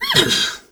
pgs/Assets/Audio/Animal_Impersonations/zebra_breath_04.wav at master
zebra_breath_04.wav